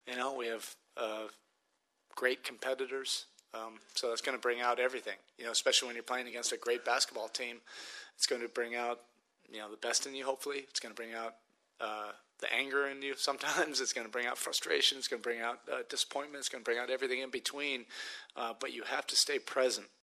Here, Head Coach Erik Spoelstra credits Boston with bringing out the best in all the Heat players: